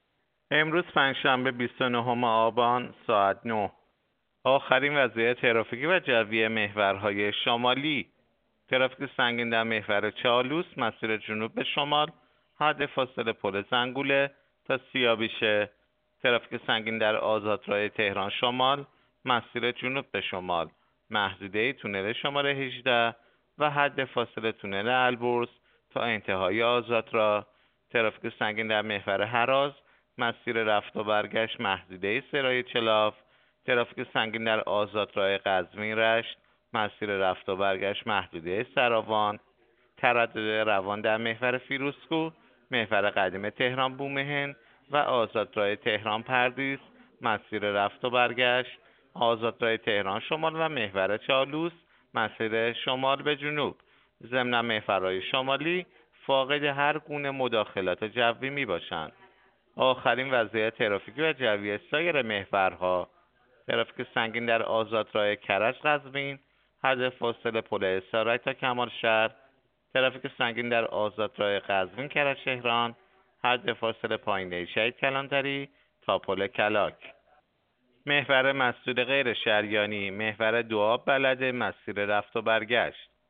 گزارش رادیو اینترنتی از آخرین وضعیت ترافیکی جاده‌ها ساعت ۹ بیست و نهم آبان؛